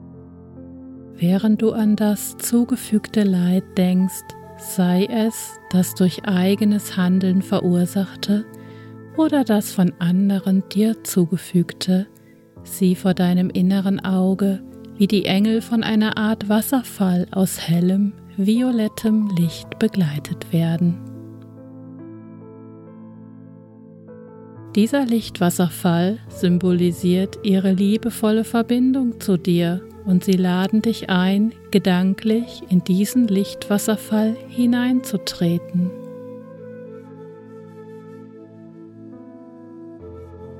Die heilsame* Kraft einer engelgeführten Vergebungsmeditation
Vergebungsmeditation-Hoerprobe.mp3